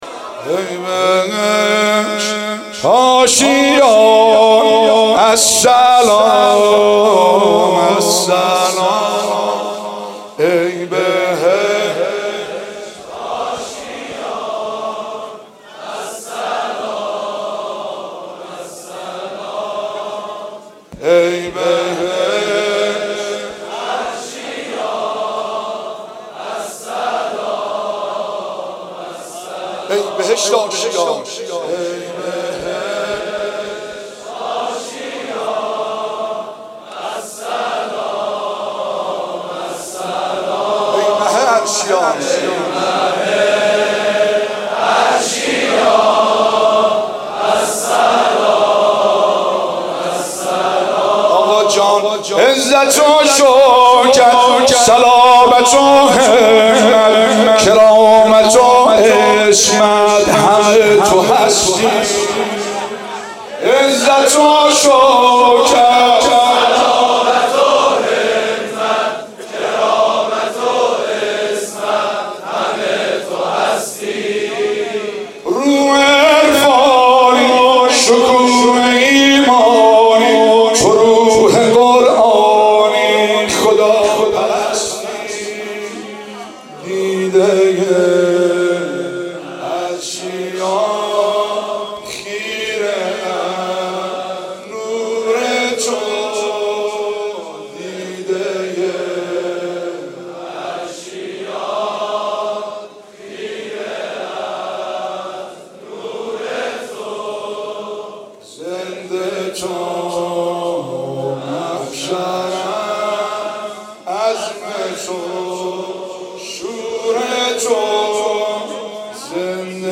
شب هشتم محرم 96 - هیئت فاطمیون - نوحه - ای بهشت آشیان السلام